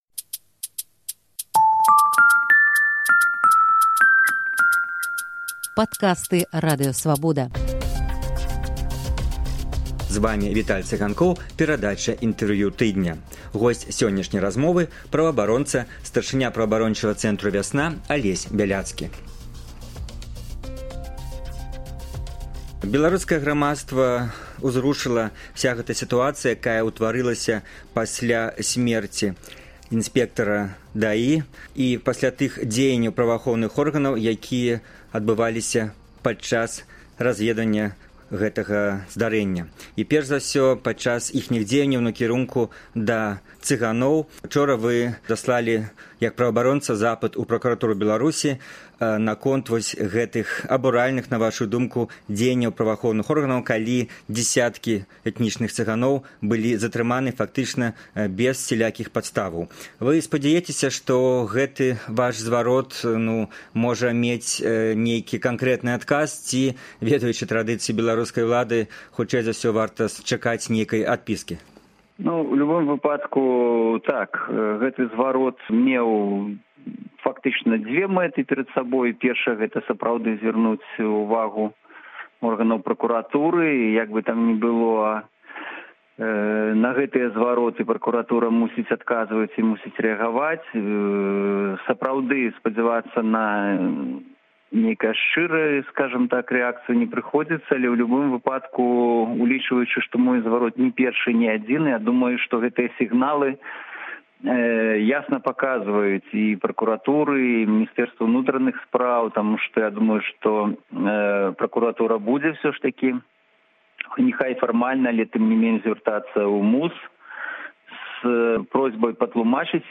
Госьць «Інтэрвію тыдня» — старшыня Праваабарончага цэнтру «Вясна» Алесь Бяляцкі. Ён заяўляе, што міліцыя фактычна абвясьціла цыганам нябачную вайну, адказвае на пытаньне, ці зьменшылася за апошні час колькасьць рэпрэсаваных, і тлумачыць, што стрымлівае ўлады ад таго, каб «разгарнуцца» па поўнай.